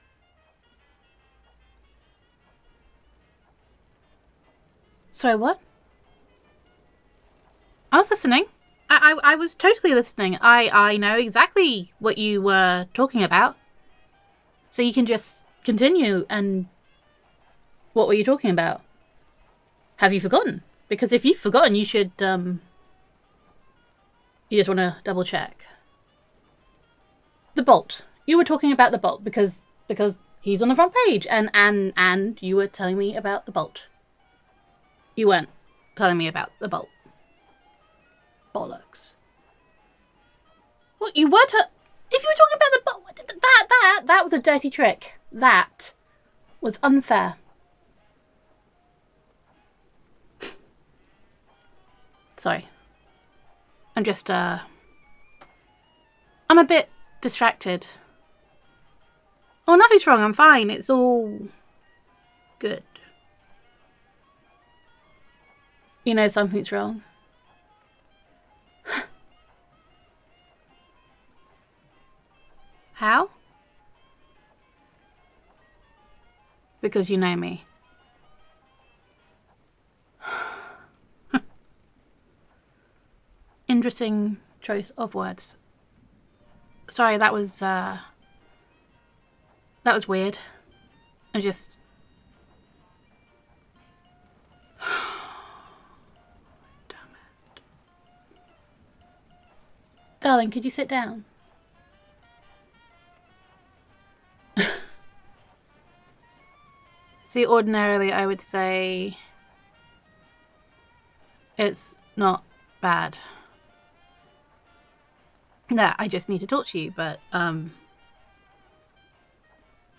[F4A] Do the Voice? [Supervillain Roleplay][Girlfriend Roleplay][Secret Identity][the Bloody Heart][I Don’t Have an Evil Voice][You Are Taking This Very Well][Did You Just Squeal?][Gender Neutral][Nervous Girlfriend Confesses That She Is a Supervillain and Is Surprised at How Well You Take It]